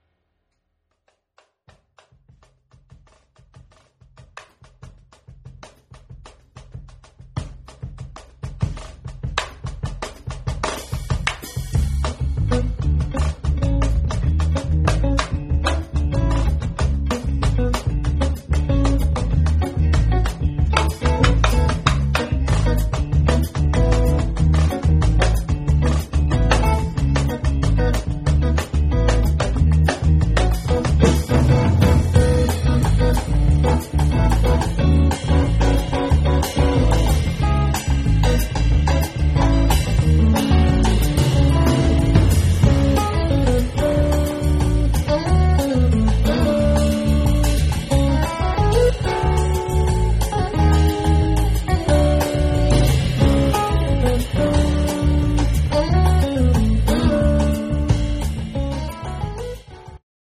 guitar
bass
Funk
Fusion
Jamband
Jazz